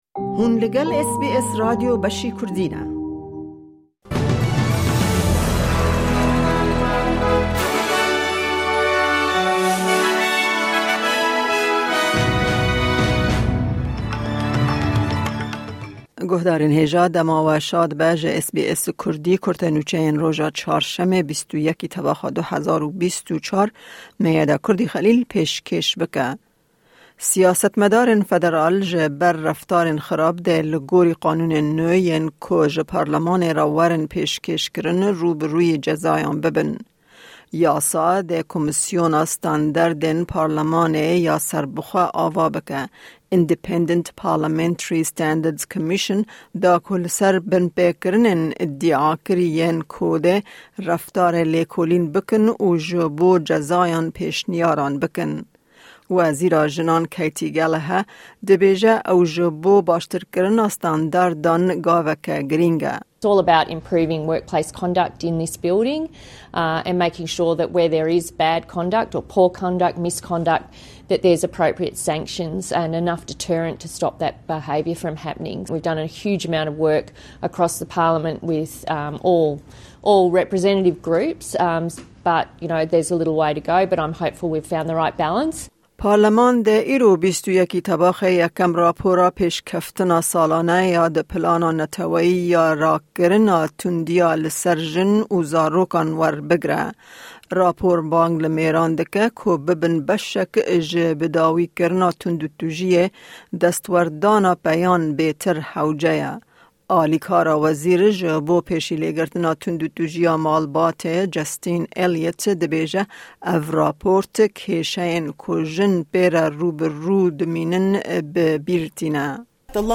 Kurte Nûçeyên roja Çarşemê 21î Tebaxa 2024